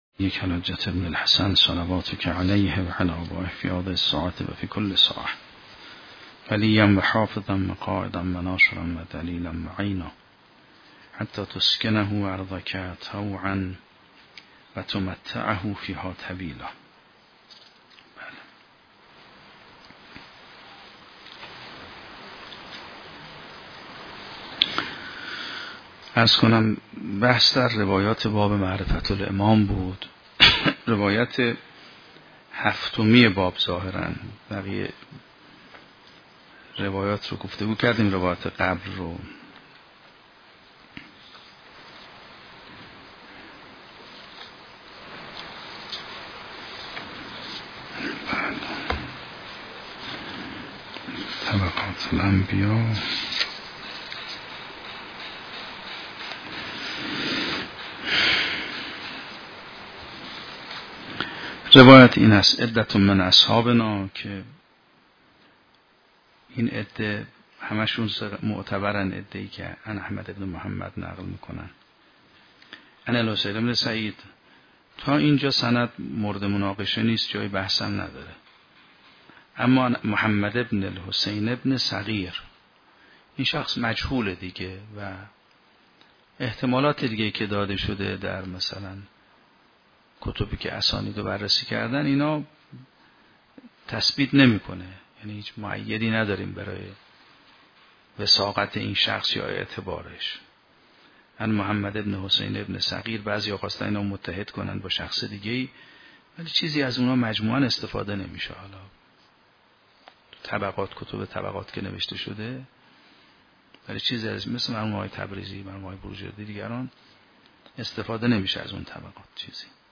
شرح و بررسی کتاب الحجه کافی توسط آیت الله سید محمدمهدی میرباقری به همراه متن سخنرانی ؛ این بخش : معتبره ربعی بن عبدالله در ارتباط معرفت امام با معرفت جمیع اشیاء